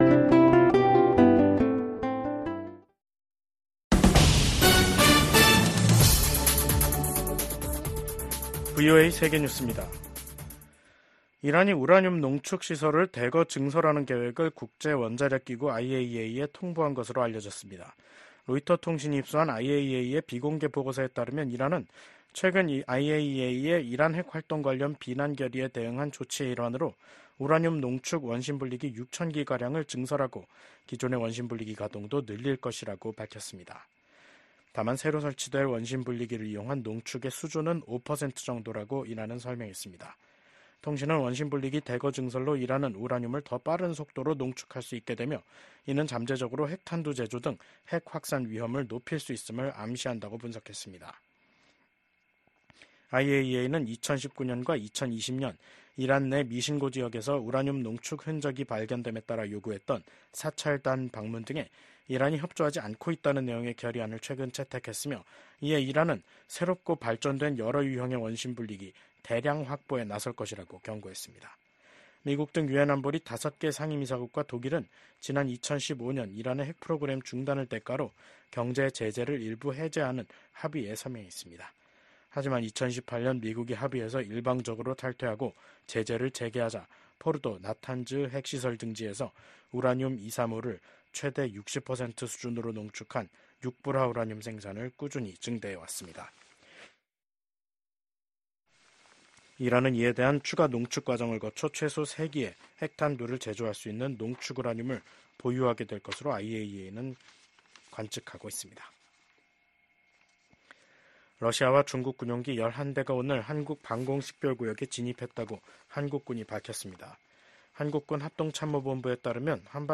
VOA 한국어 간판 뉴스 프로그램 '뉴스 투데이', 2024년 11월 29일 3부 방송입니다. 우크라이나와의 전쟁이 치열해지는 가운데 러시아의 안드레이 벨로우소프 국방장관이 북한을 공식 방문했습니다. 미국 국무부가 북한 국적자를 포함한 개인 3명과 러시아, 중국 회사에 제재를 단행했습니다.